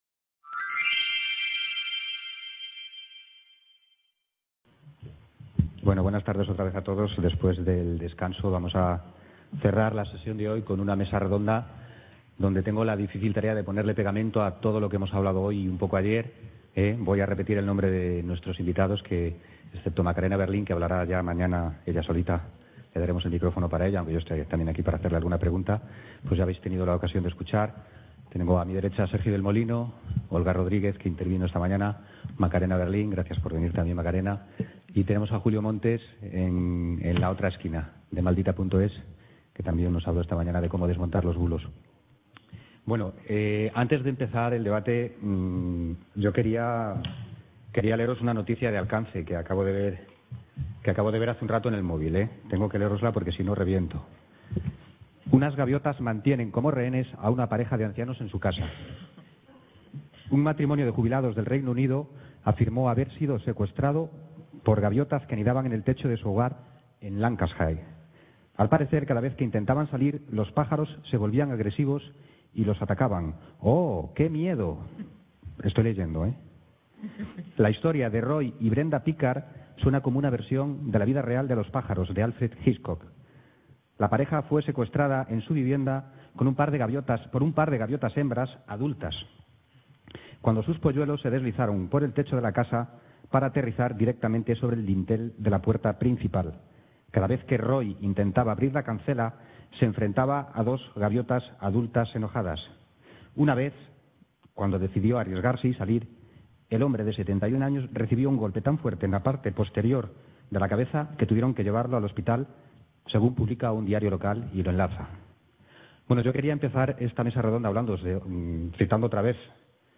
Periodismo narrativo en tiempos de noticias falsas (Mesa redonda